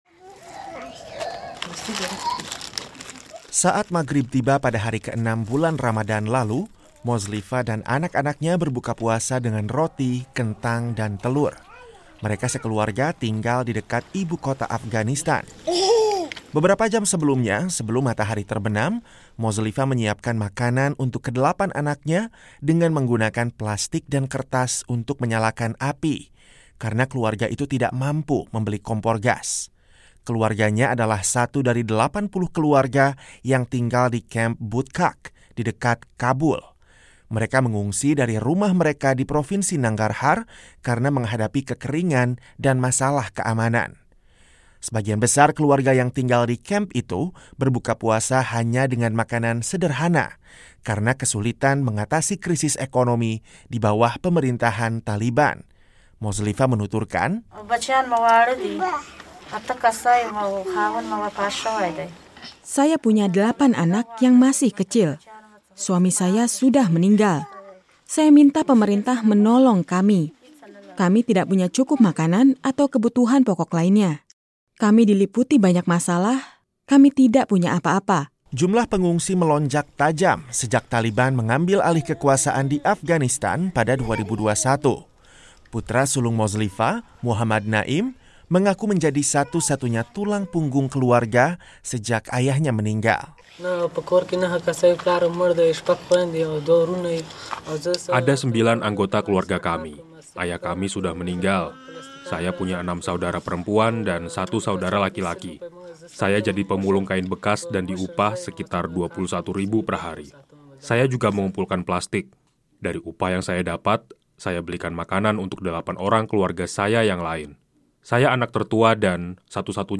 Ketika umat Islam merayakan bulan suci Ramadan dengan berbagai dekorasi dan penganan khas, banyak warga muslim di Afghanistan yang justru kesulitan mencari sesuap nasi. Laporan AP berikut ini menggambarkan perjuangan keluarga Afghanistan yang didera kemiskinan di bawah pemerintahan Taliban.